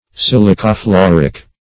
Silicofluoric \Sil`i*co*flu*or"ic\, a. (Chem.)